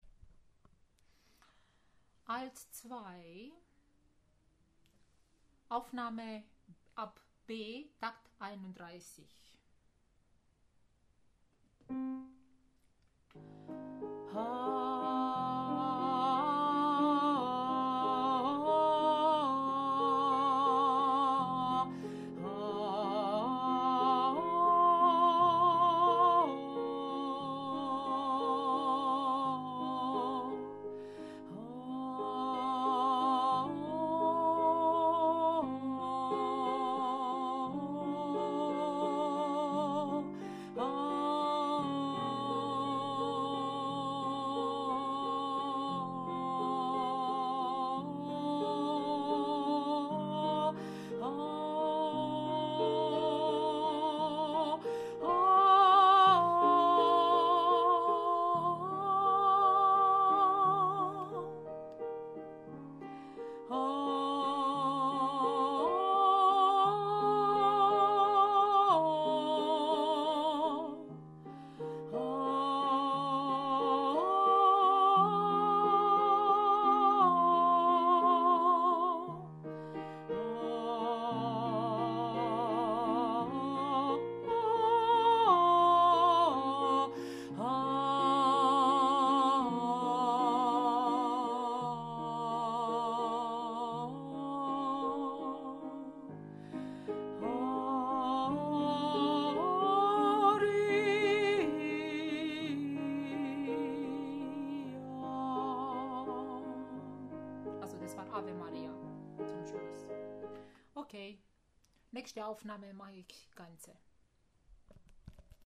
Ave Maria – Alto 2-TeilB
Ave-Maria-A2-Teil-B.mp3